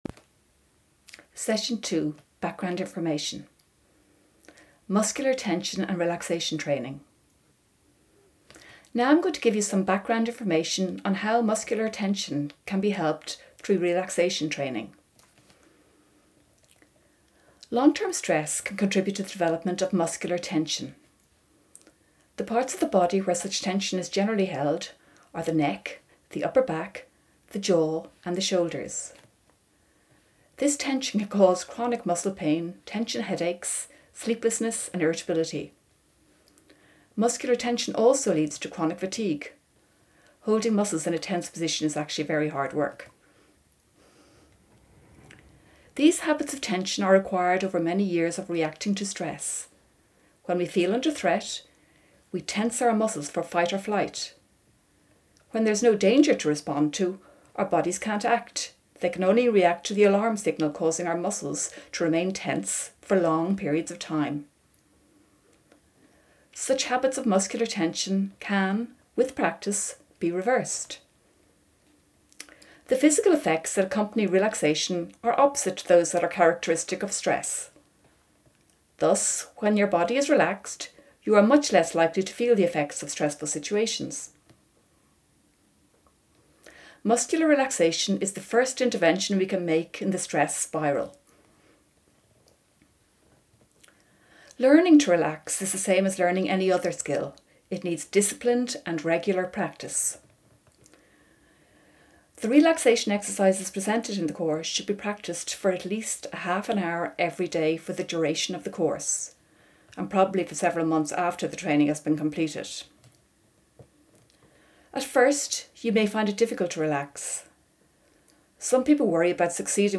SMTP session 2 Lecture providing background information on Muscular Relaxation as a treatment for stress